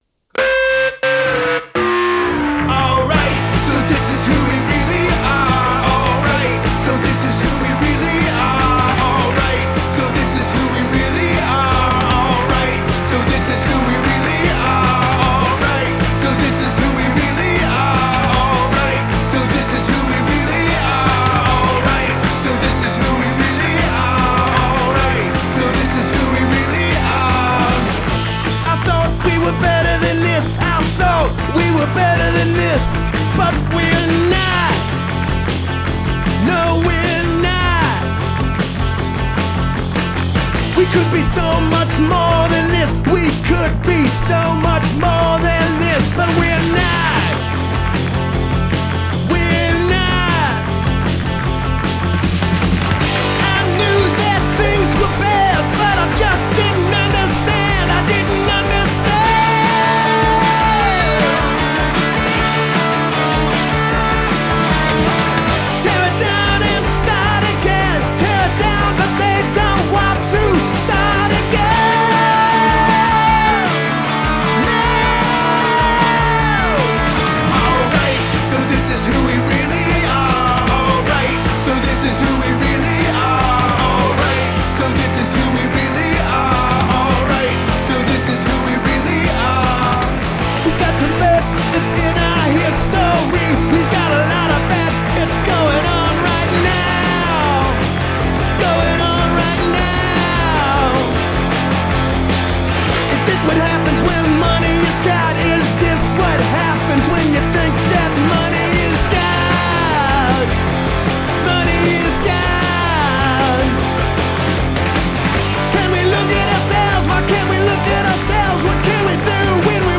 It’s punk rock. It’s loud.